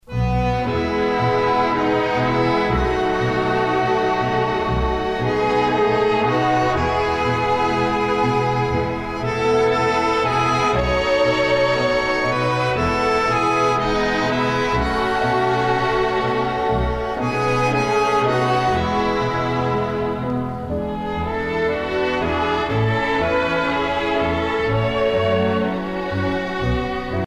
danse : tango
Pièce musicale éditée